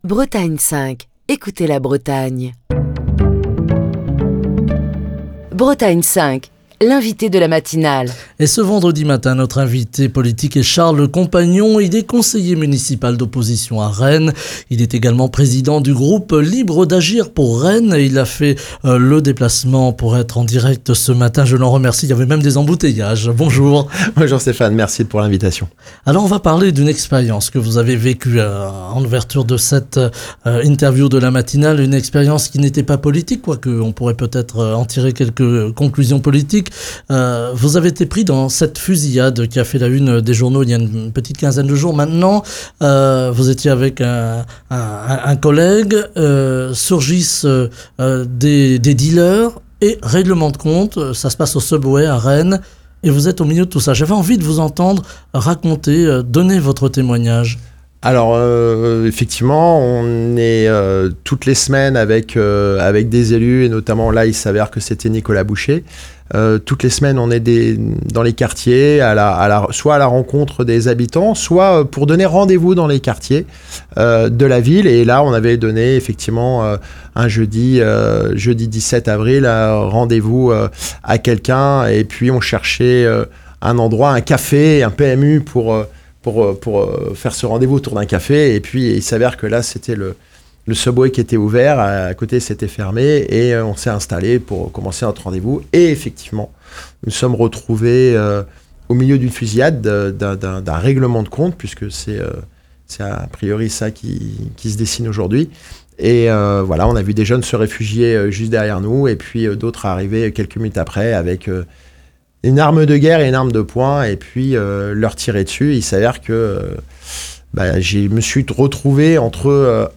Ce vendredi, Charles Compagnon, conseiller municipal d’opposition à Rennes et président du groupe Libres d’Agir pour Rennes, était l’invité politique de la matinale de Bretagne 5. Au micro de Bretagne 5 Matin, l’élu rennais est revenu sur un épisode marquant : la fusillade à laquelle il s’est trouvé involontairement confronté le 17 avril dernier, alors qu’il déjeunait dans un restaurant Subway, sur la dalle Kennedy.